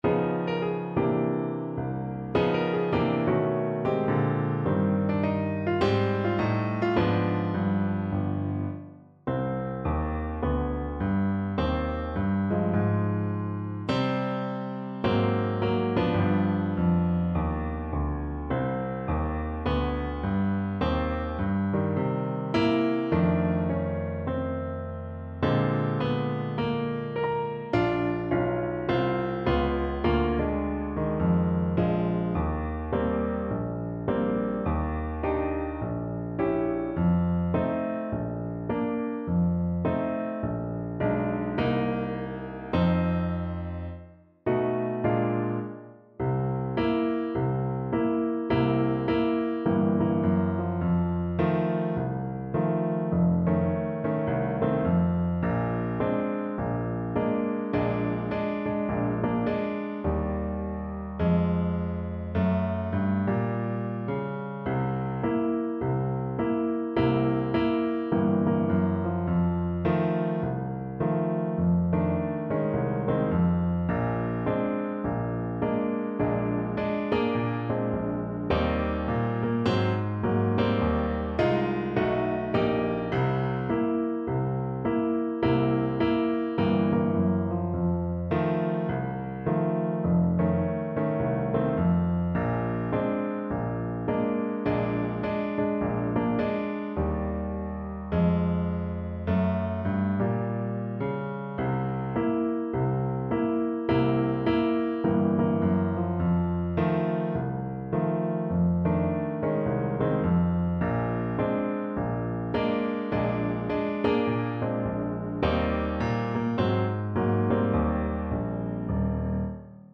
Slowly = 52
2/2 (View more 2/2 Music)
Arrangement for Trombone and Piano
Pop (View more Pop Trombone Music)